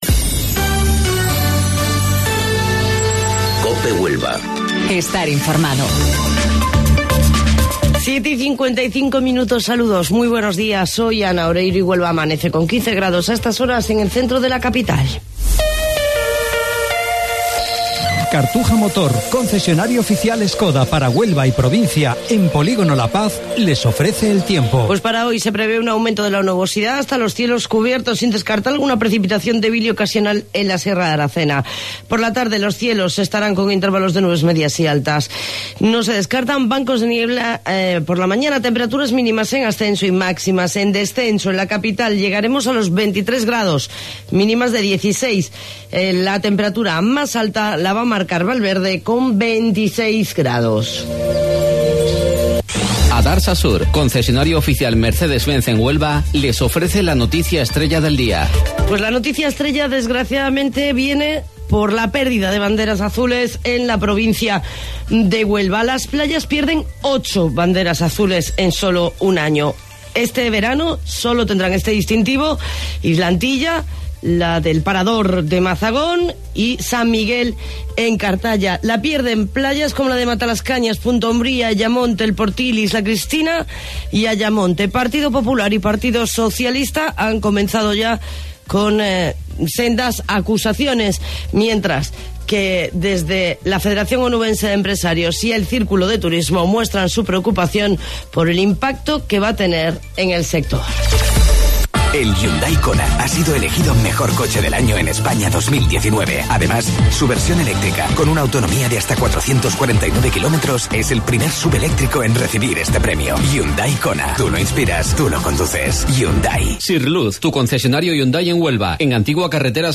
AUDIO: Informativo Local 07:55 del 8 de Mayo